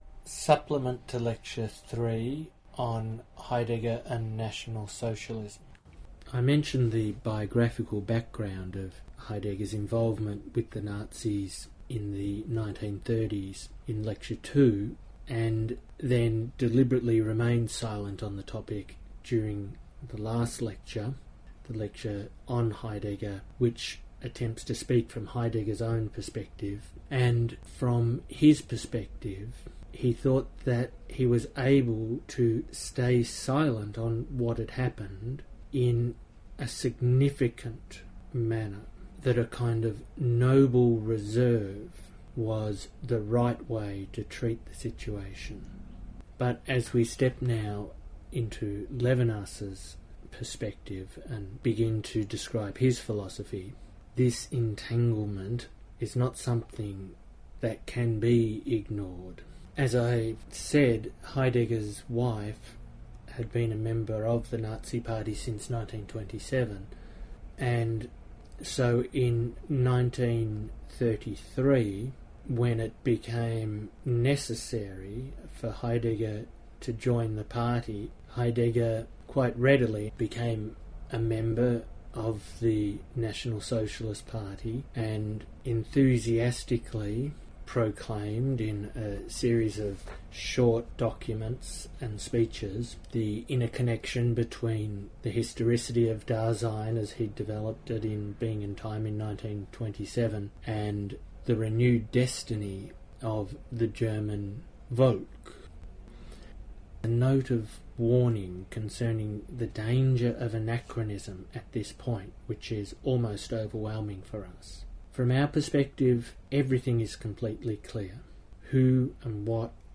Lecture 3-supplement-on-Heidegger-and-Nazism.mp3